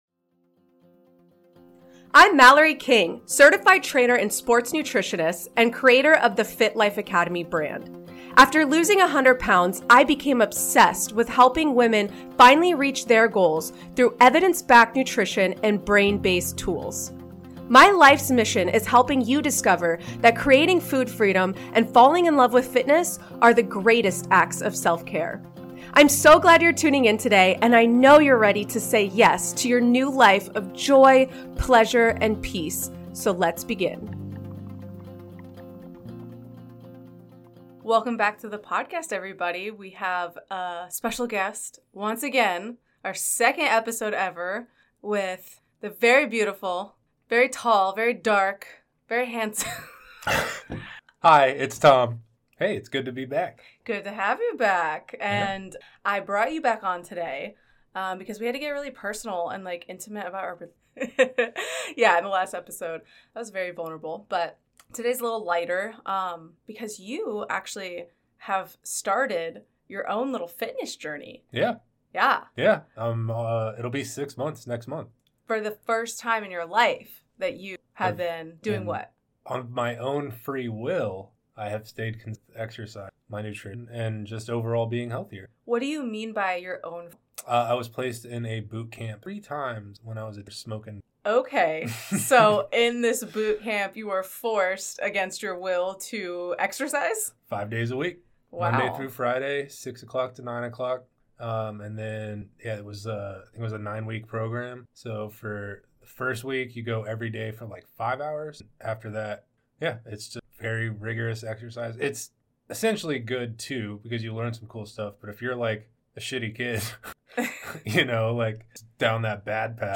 for an intimate conversation about overcoming gym anxiety and embracing a fitness journey together.